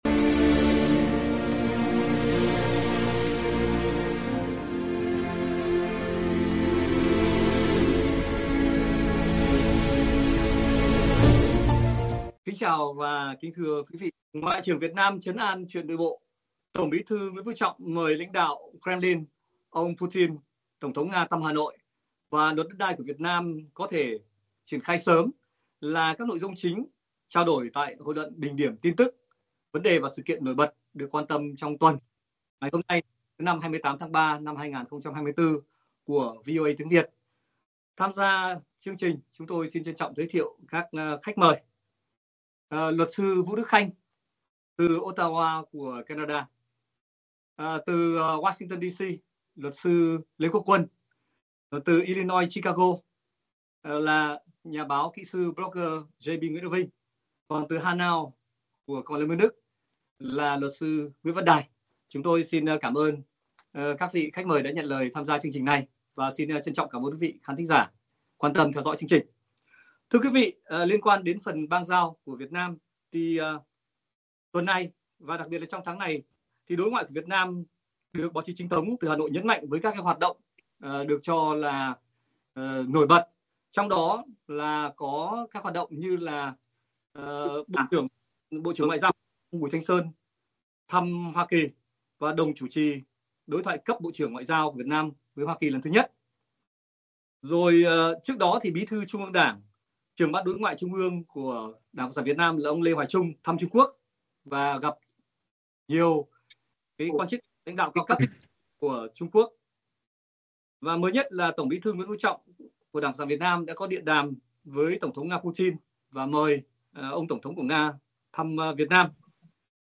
Hội luận thứ Năm 28/3/2024 bình điểm các tin tức, vấn đề, sự kiện nổi bật được quan tâm trong tuần từ thay đổi nhân sự cấp cao lãnh đạo nhà nước Việt Nam, đến các diễn biến xét xử các vụ án trong chiến dịch ‘đốt lò’ chống tham nhũng do ĐCSVN phát động.